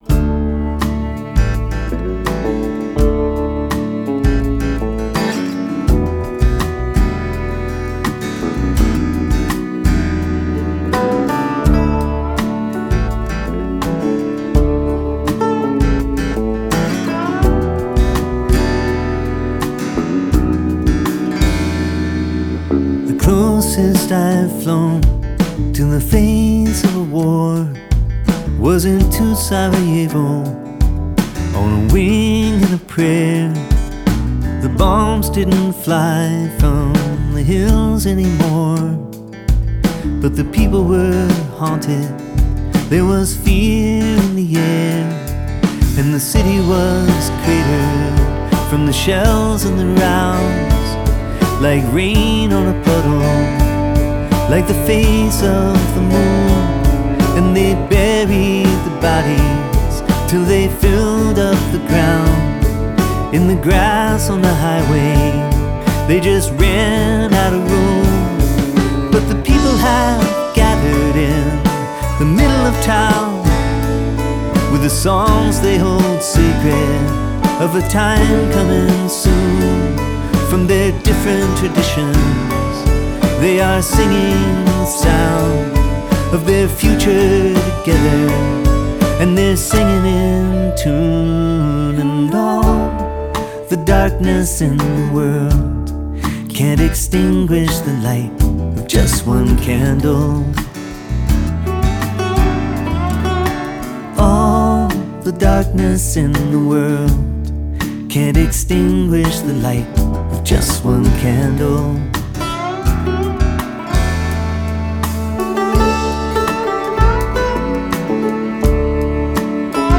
Singer/Songwriter (Solo) piece